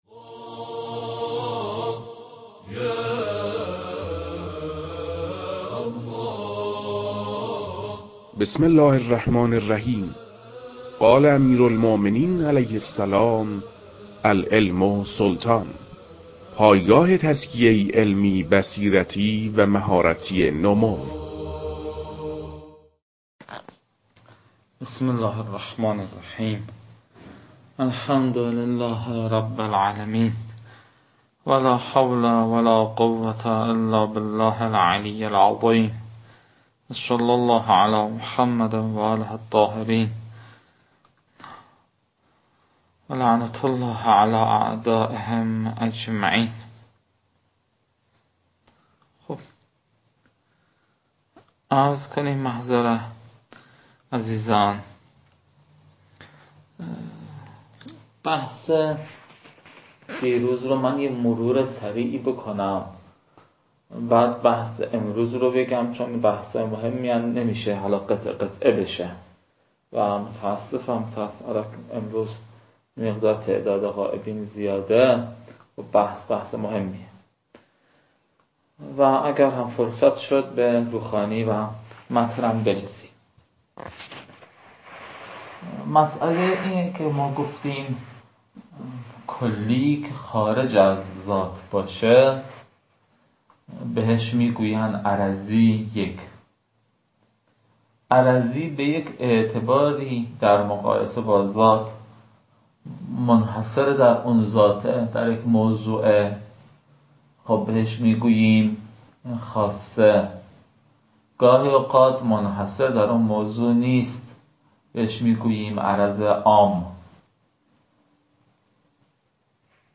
در این بخش، کتاب «منطق مظفر» که اولین کتاب در مرحلۀ شناخت علم منطق است، به صورت ترتیب مباحث کتاب، تدریس می‌شود.